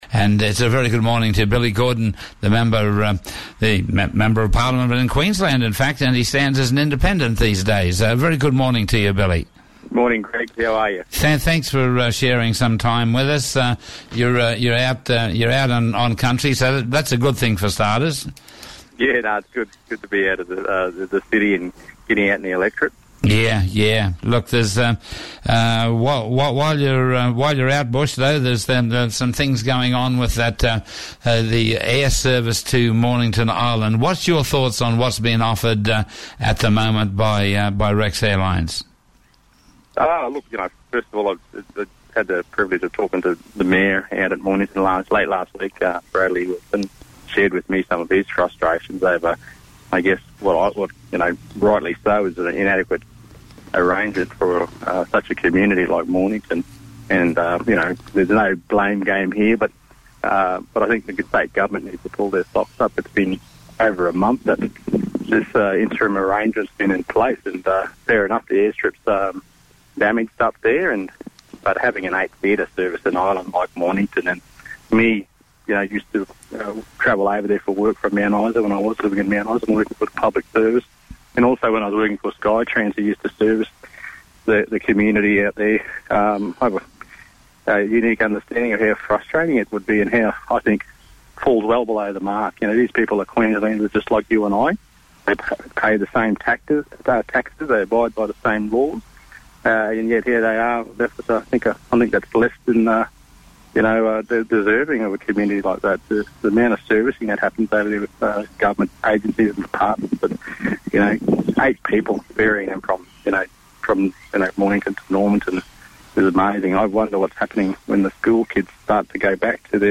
Gulf & Cape Leaders spoke with Black Star Radio on the re-opening of the Islands Airport.
State Member for Cook MP Billy Gordon on Black Star Breakfast 18 January 2016